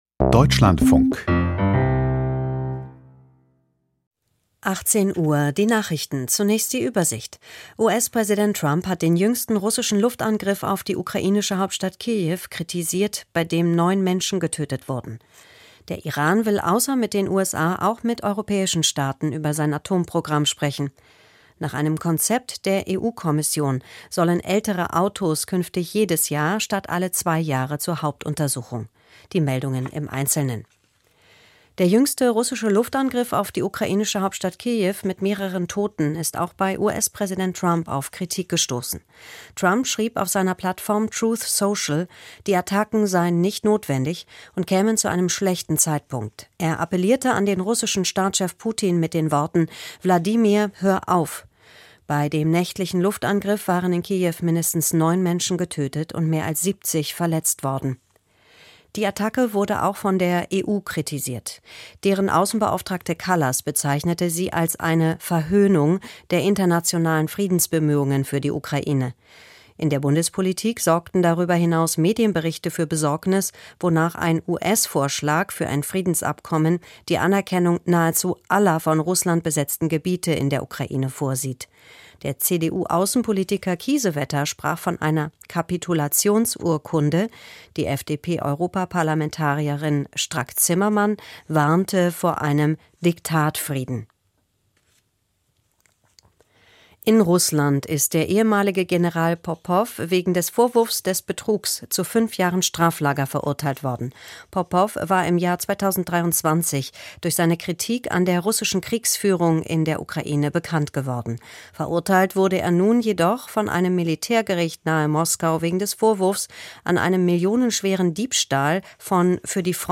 Die Deutschlandfunk-Nachrichten vom 24.04.2025, 18:00 Uhr